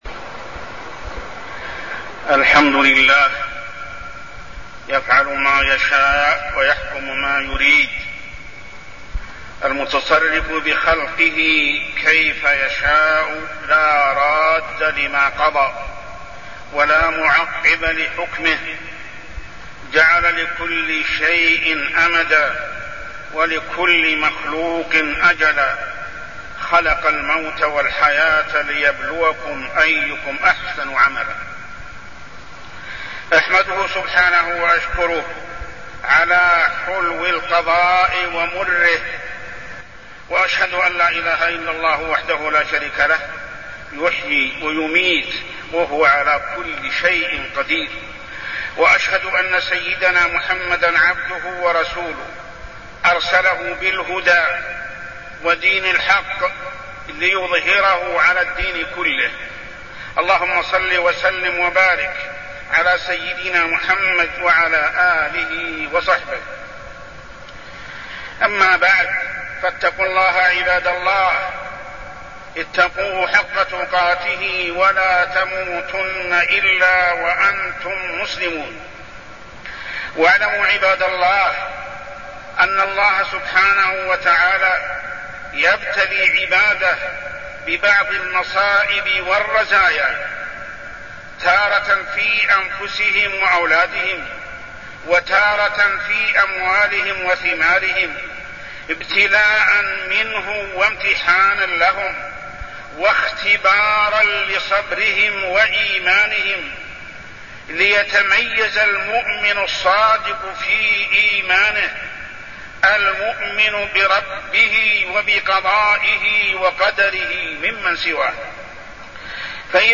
تاريخ النشر ٢٨ محرم ١٤٢٠ هـ المكان: المسجد الحرام الشيخ: محمد بن عبد الله السبيل محمد بن عبد الله السبيل الصبر على المصيبة